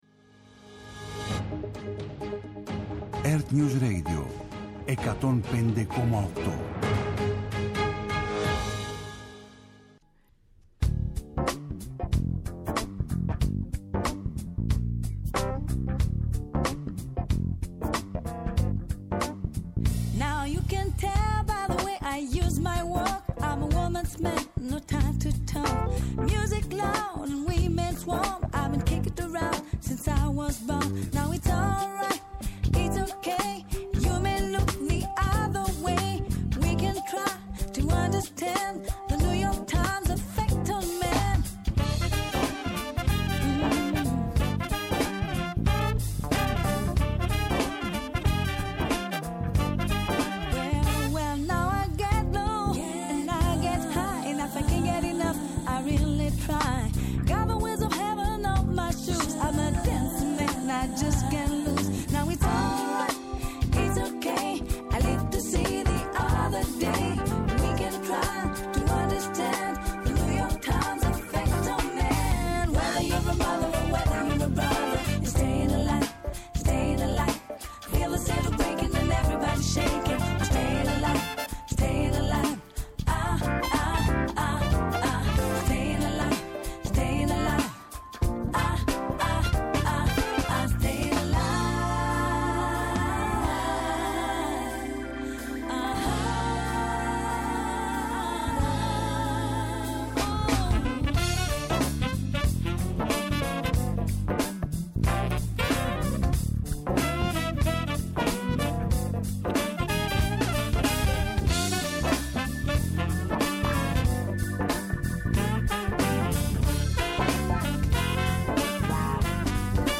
Σήμερα καλεσμένος στο στούντιο ο Δήμαρχος Αθηναίων Χάρης Δούκας.